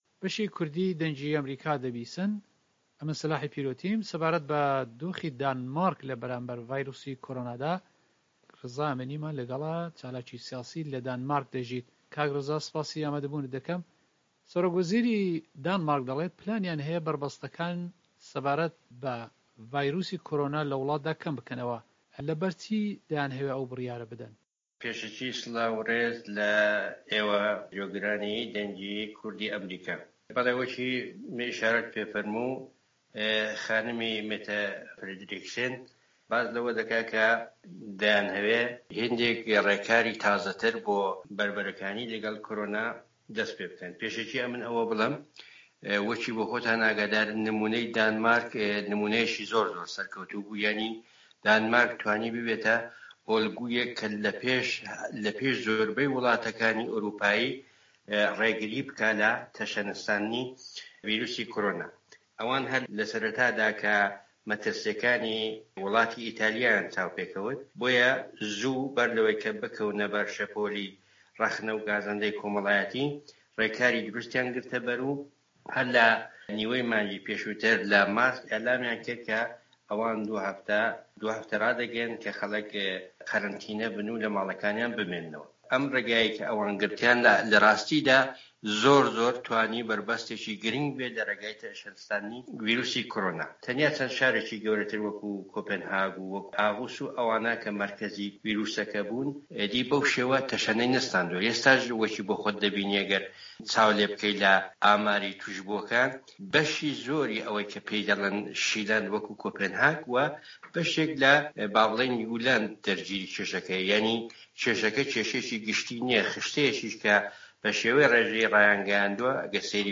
لە وتووێژێک دەگەڵ دەنگی ئەمەریکا